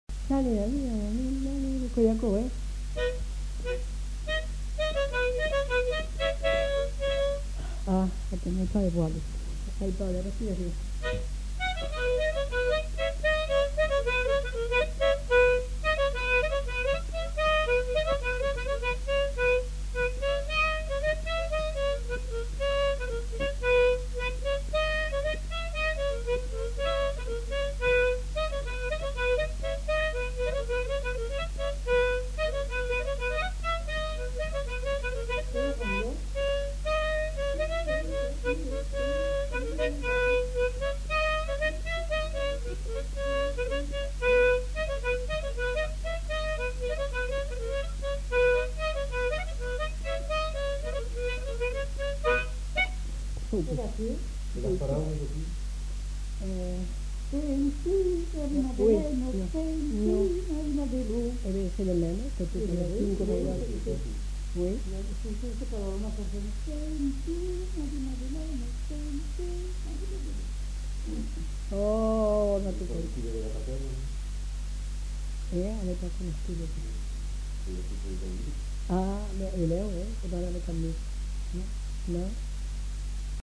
Aire culturelle : Haut-Agenais
Lieu : Cancon
Genre : morceau instrumental
Instrument de musique : harmonica
Danse : rondeau
Notes consultables : L'informatrice annonce le pripet.